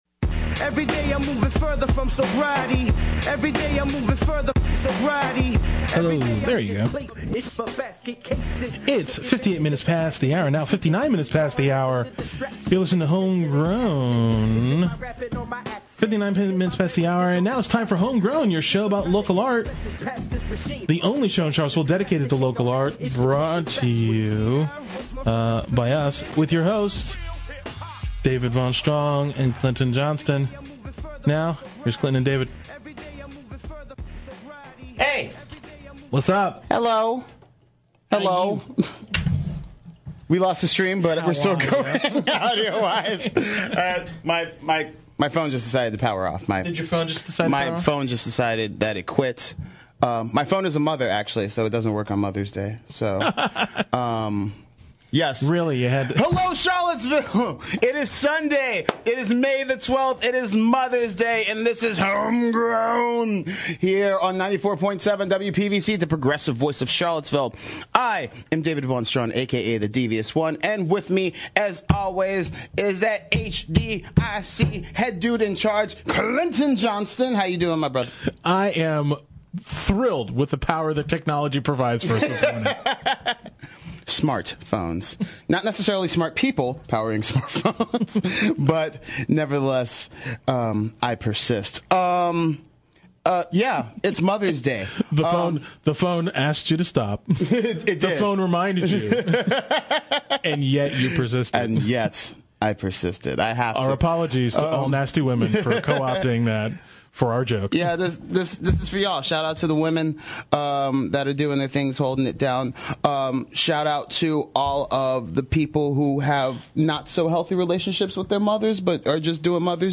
Two shots at theater with a touch of great music on this Sunday’s Home Grown.